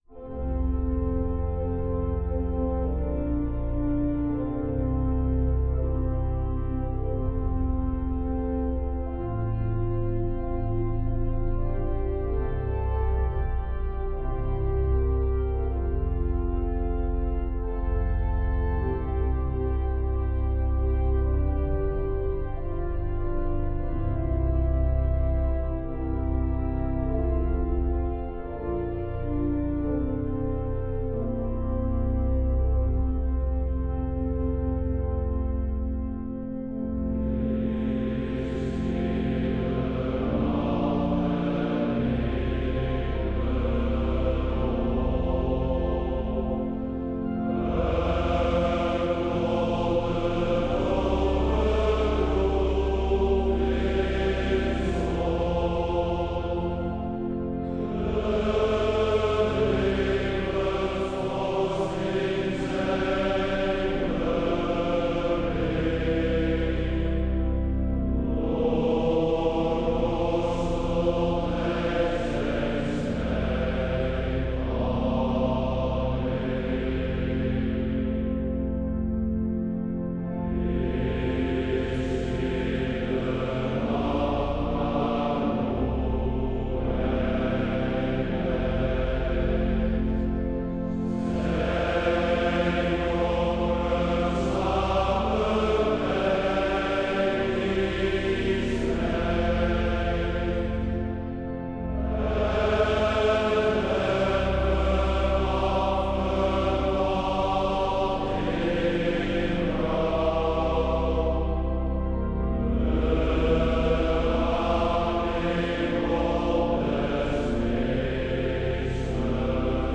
Orgel
De onderstaande nummers zijn op deze avond gezongen.